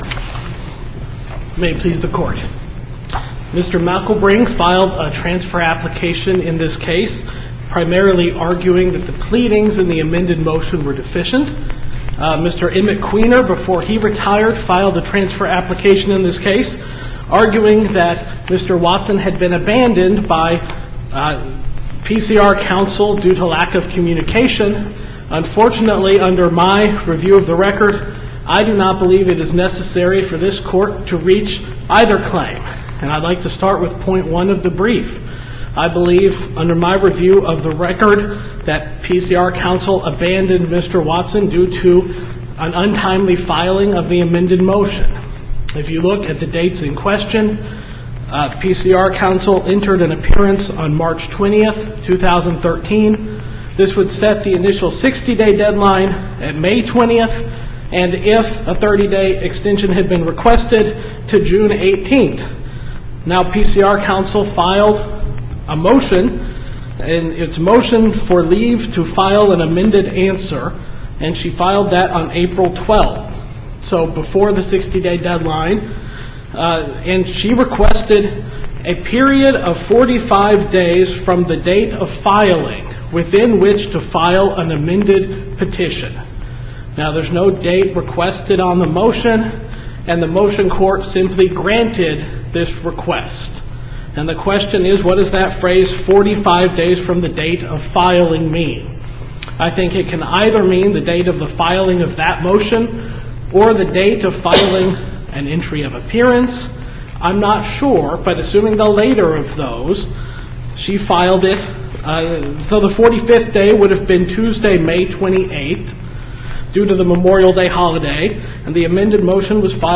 MP3 audio file of arguments in SC96103
SUPREME COURT OF MISSOURI
Challenge to denial of postconviction relief without an evidentiary hearing Listen to the oral argument